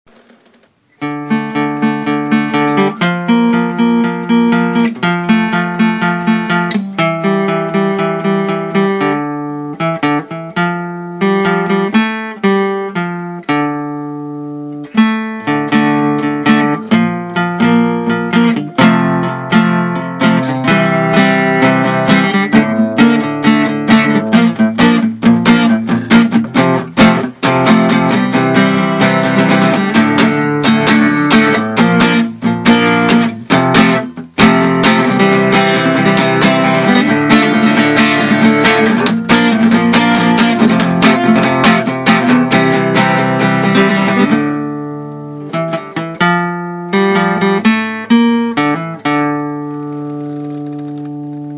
描述：吉他的一个主题
标签： 旋律 声音 干净 吉他 谐波 电影 和弦 古典
声道立体声